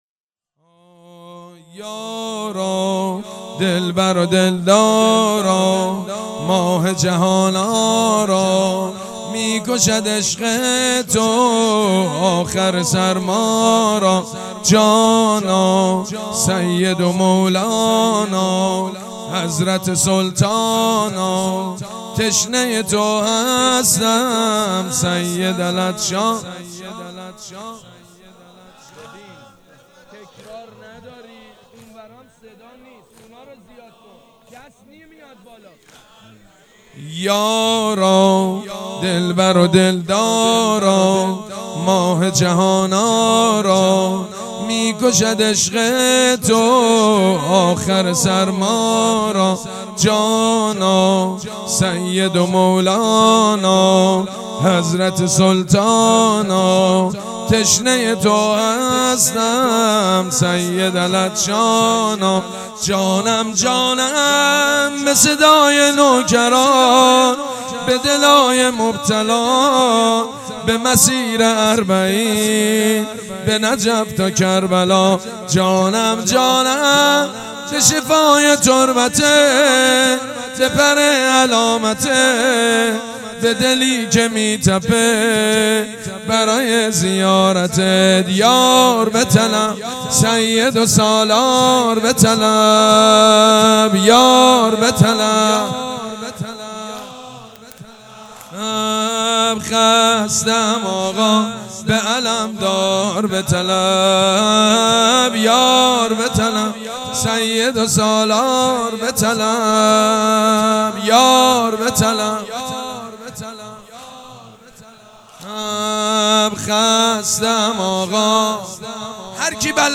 سبک اثــر پیش زمینه مداح حاج سید مجید بنی فاطمه
مراسم اربعین